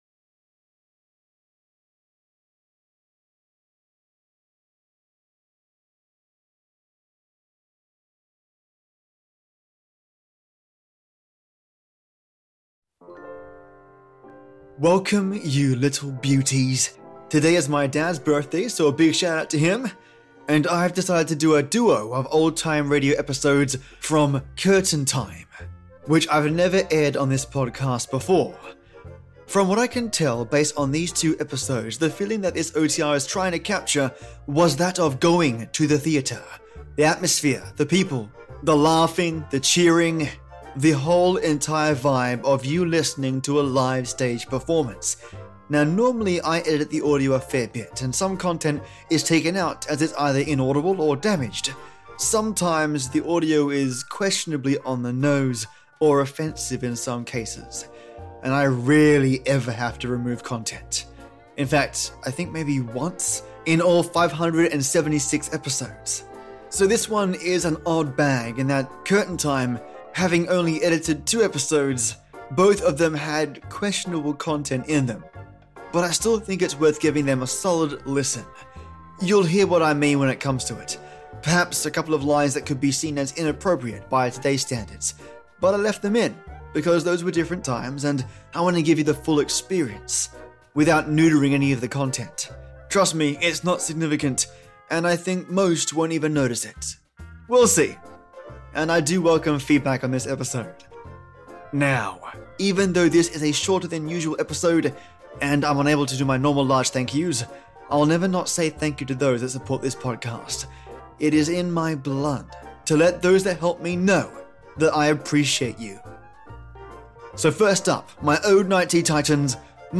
From what I can tell based on these two episodes, the feeling that this OTR tried to capture was that of, going to the theatre. So as if you’re listening to a live stage performance. Now normally I edit the audio a fair bit, and some content is taken out as it’s either in audible, or damaged.